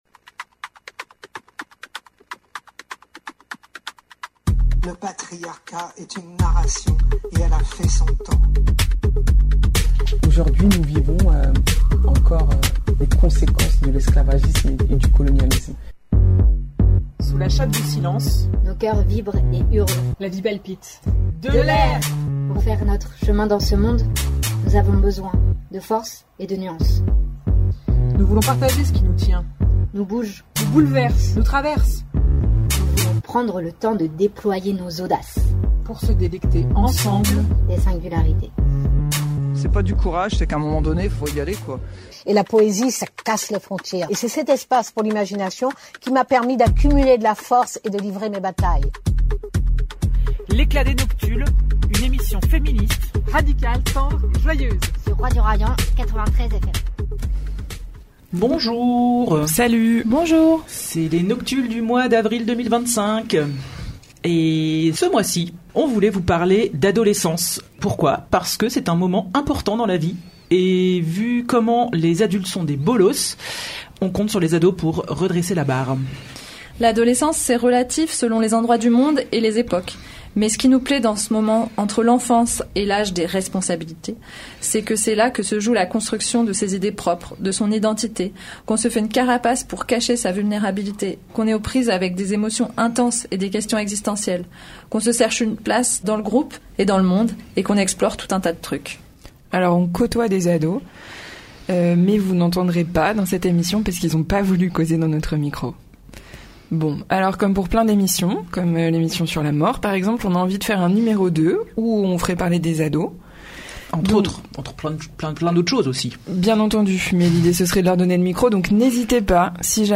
Ce mois-ci, on tricote autour de cette période intense qu’est l’adolescence, entre l’enfance et l’âge des responsabilités. Les ados n’ont pas voulu causer à notre micro mais vous entendrez des chroniques culturelles, des lectures, des anecdotes, des rires, des histoires de santé mentale et de santé sexuelle et un blind test sur des séries des années 90.